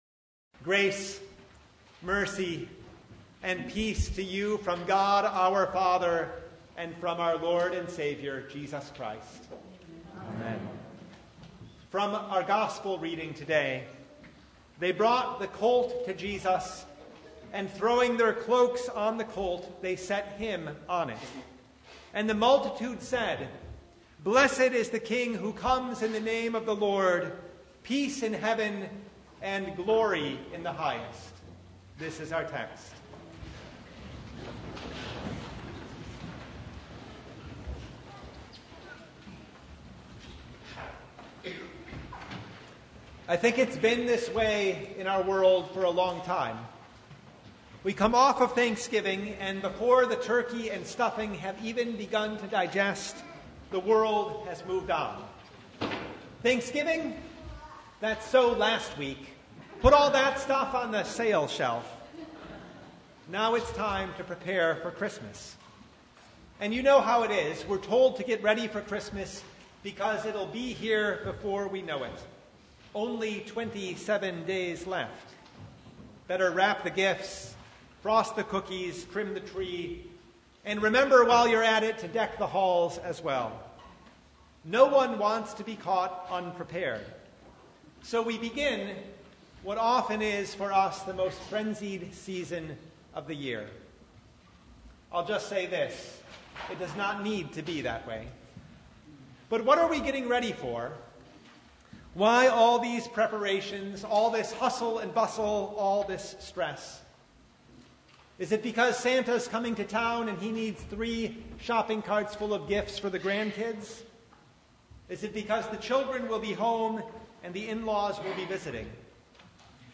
Luke 19:28-40 Service Type: Advent Who is this God?